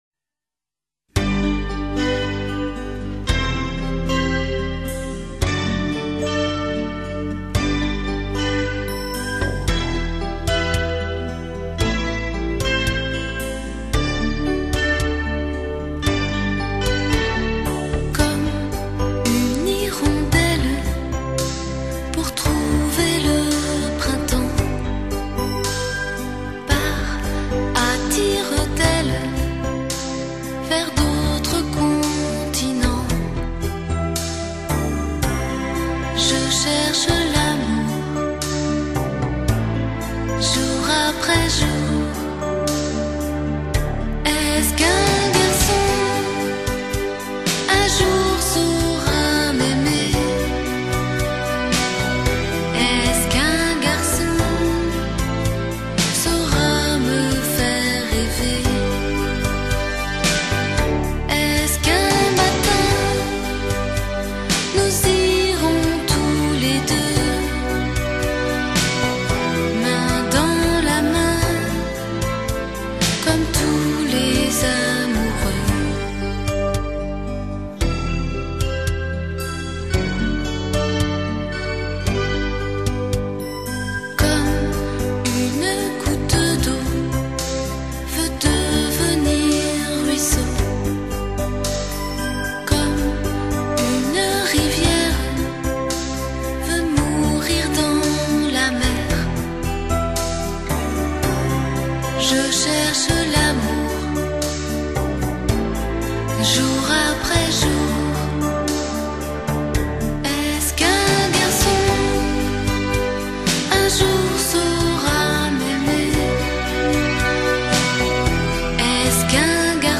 감미로운 샹송